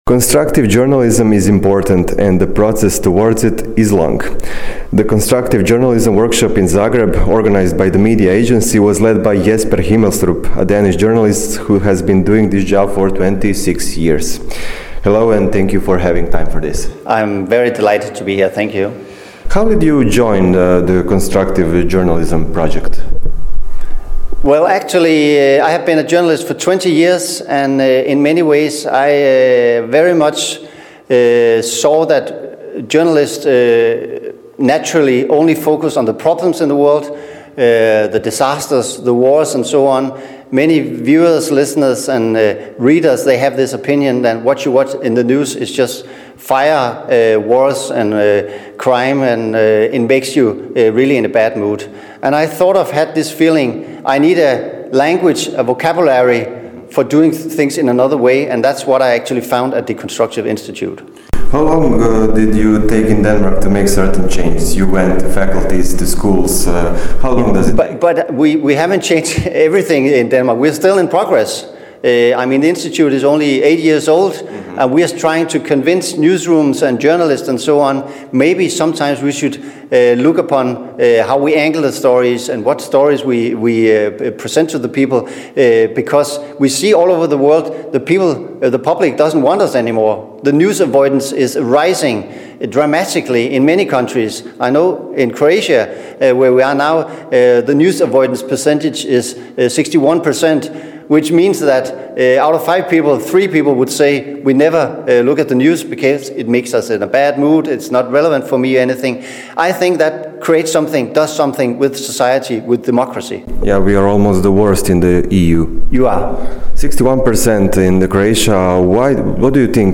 Intervju je na engleskom jeziku.